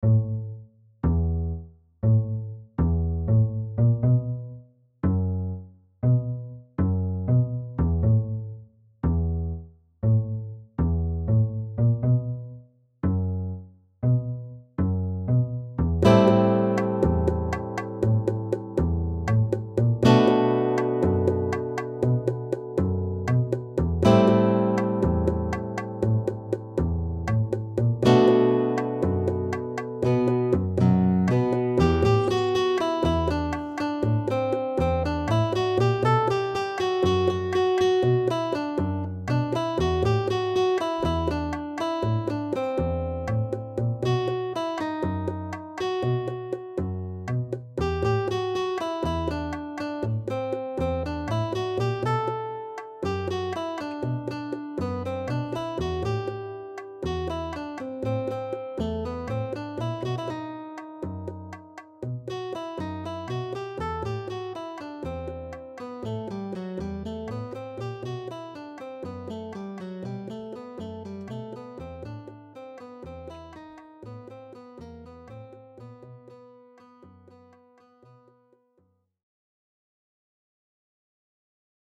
Dabei handelt es sich um eine Konzertgitarre, bei der es diverse Klangmöglichkeiten gibt.
Dieses Klangbeispiel wurde mit dem „Warm“ Preset aufgenommen.